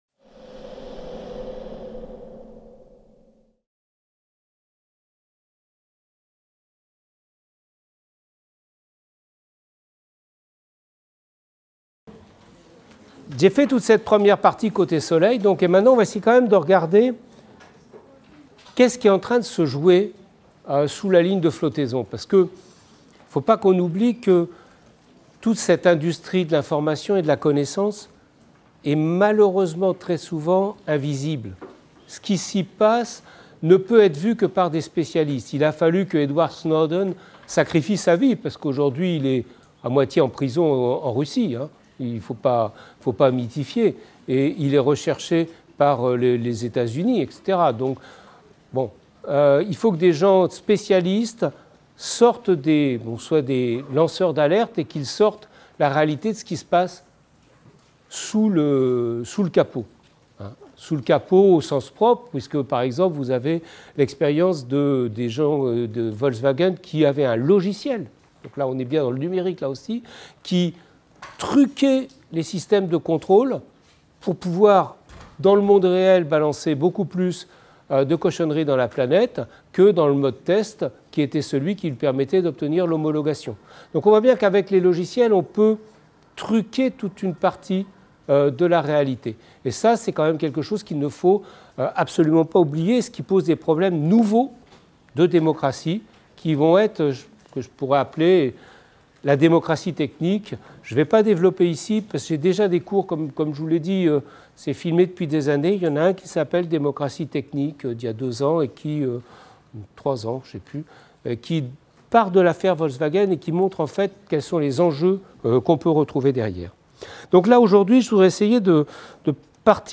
Cours de Culture numérique dans le cadre de la Licence Humanités parcours Humanités numériques.